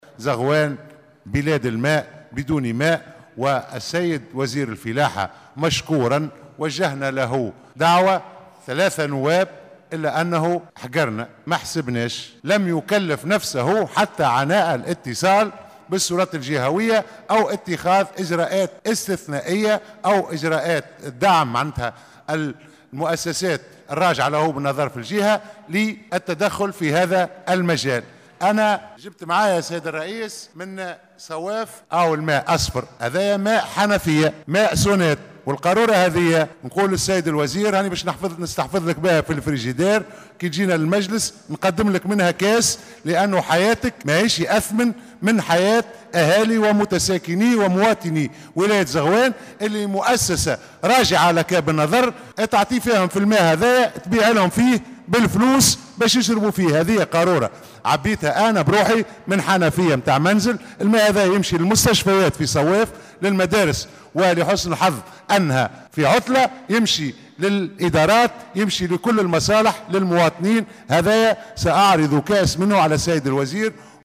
خلال جلسة عامة بالبرلمان عقدت اليوم الثلاثاء 10 جويلية 2018 حول الوضع العام بالبلاد والنظر في عدد من مشاريع القوانين بحضور عدد من الوزراء، عبرّ النائب عن نداء تونس "محمد رمزي خميس" عن استيائه من نوعية ماء الشرب في منطقة صوّاف و غيرها من المناطق بولاية زغوان.